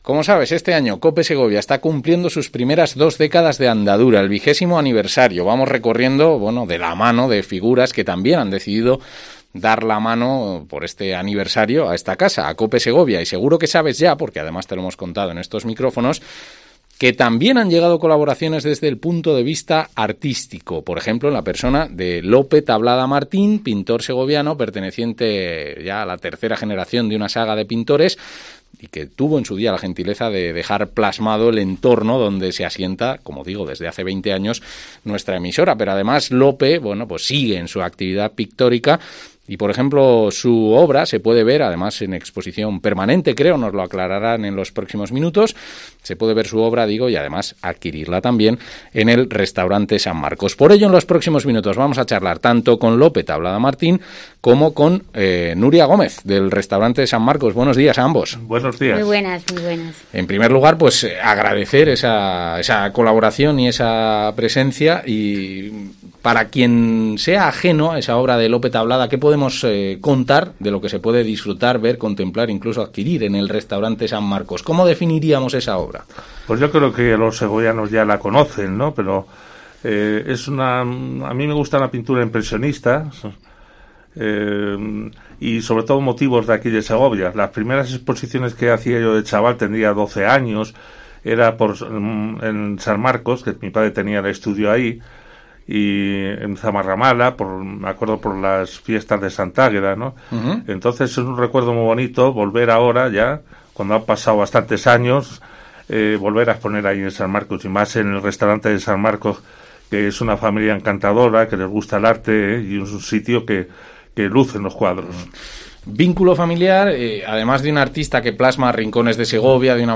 Herrera en Cope Segovia Entrevista